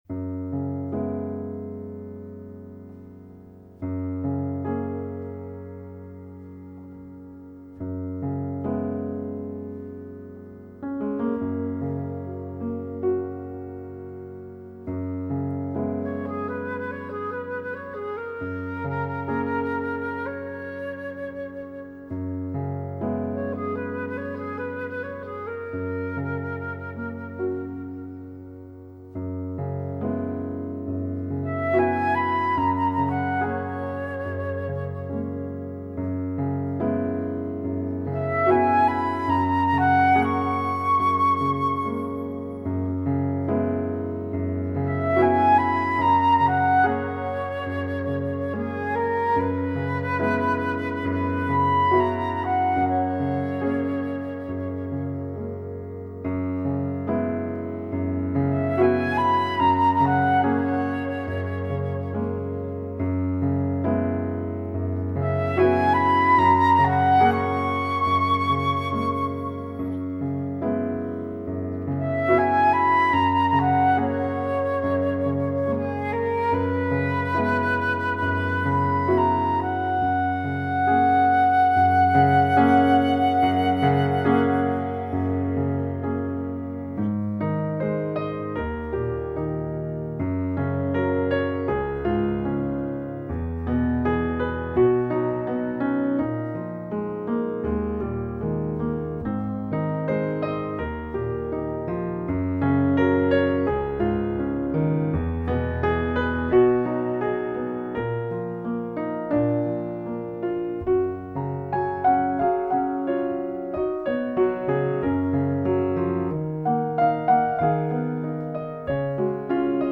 Album instrumental